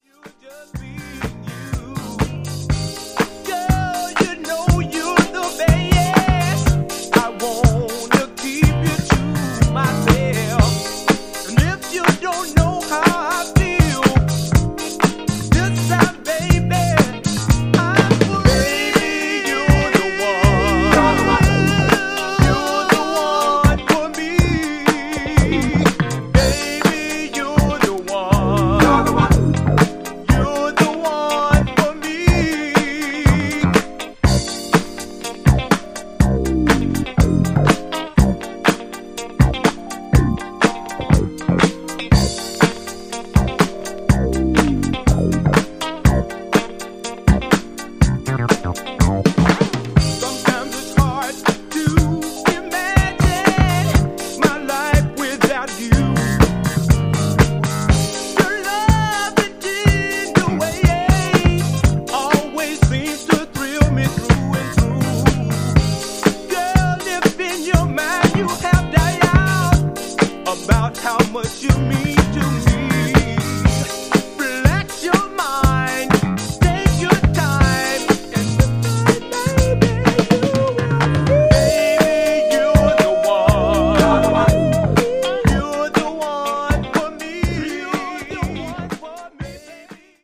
ジャンル(スタイル) FUNK / MODERN SOUL